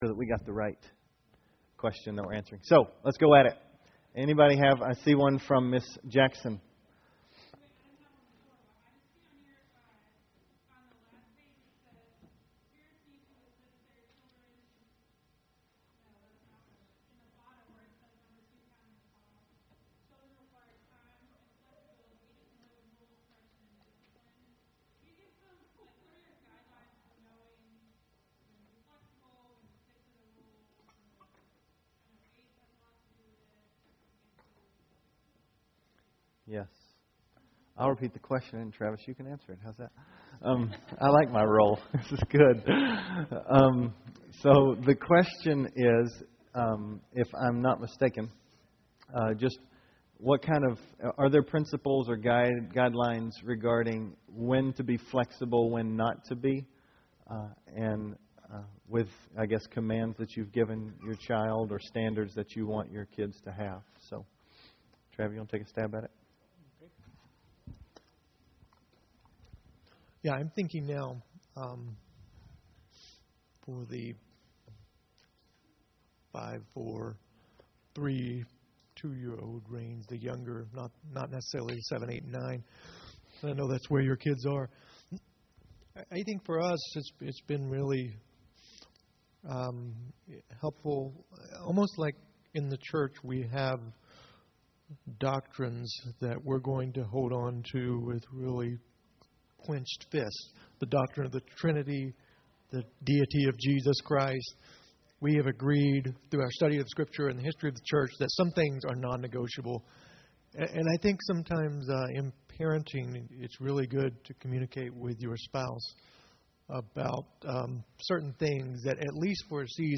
Family Room Breakout Session